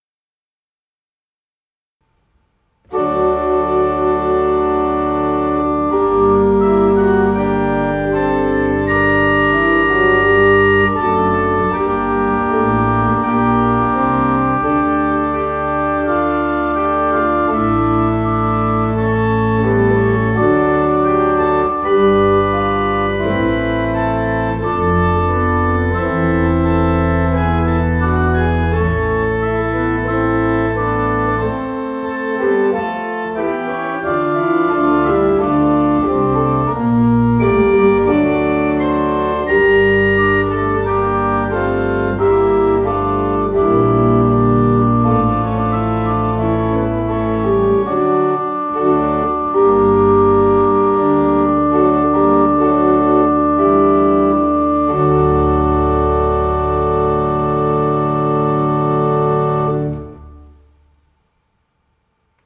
Orgel
Unsere Orgel (Orgel-Mayer, Heusweiler) wurde im Sommer 2012 durch die Orgelbaufirma Förster & Nicolaus (Lich) gründlich gereinigt und repariert und hat einen vollen und harmonischen Klang.